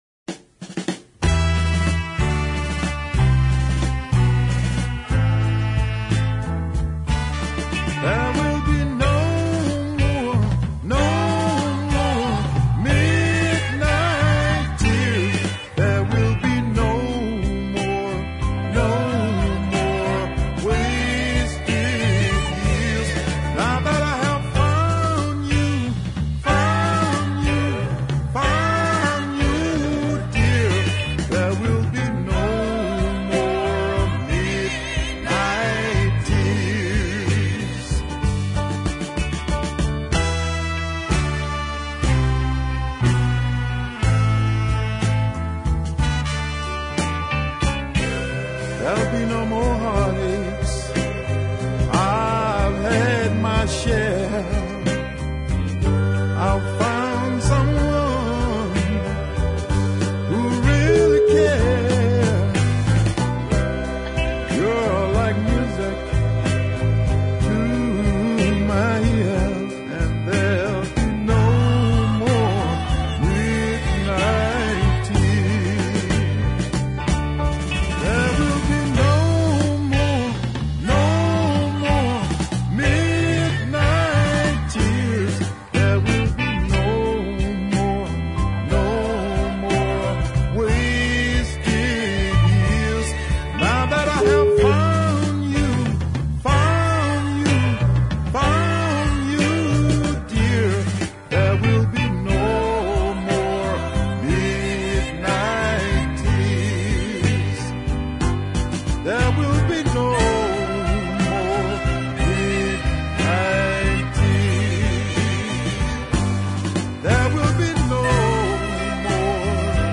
a fine piece of Nashville soul all right